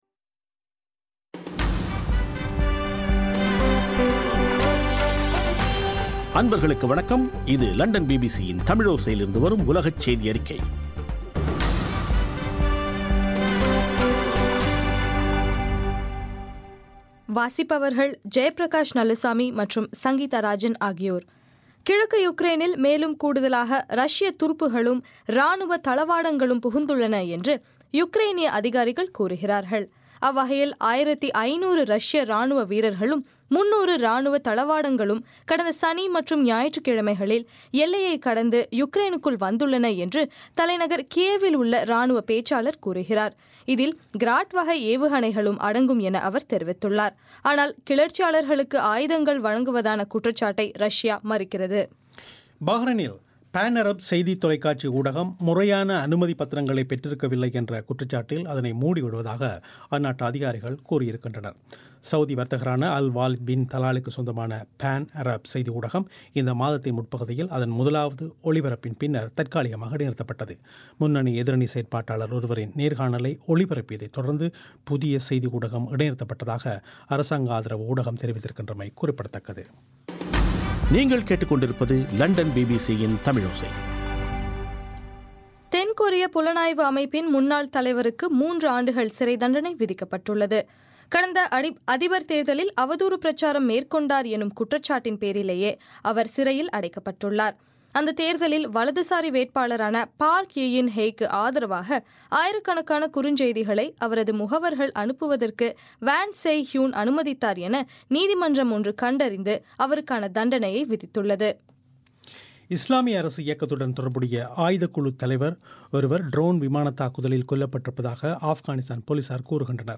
பிப்ரவரி 9 2015 பிபிசி தமிழோசையின் உலகச் செய்திகள்